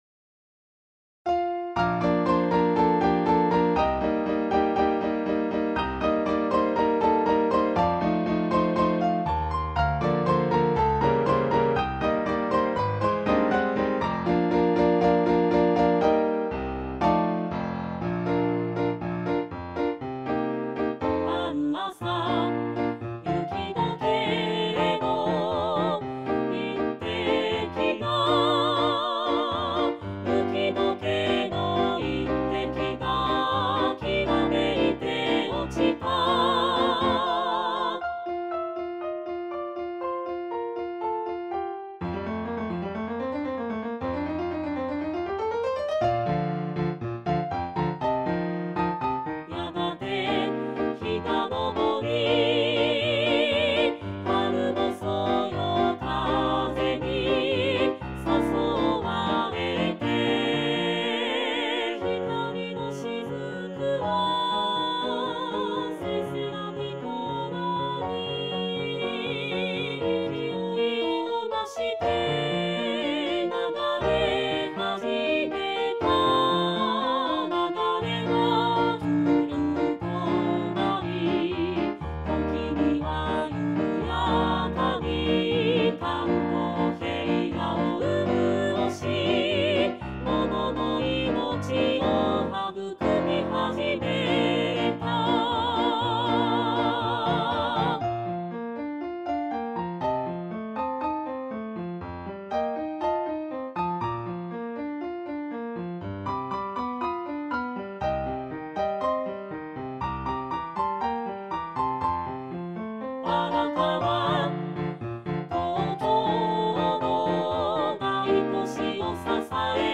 ジョイントコンサート練習曲
●パート単独音源　　　■Rchソプラノ、Lch、アルト、テノール、バス
jokyoku_arakawa_alto_sita_all.mp3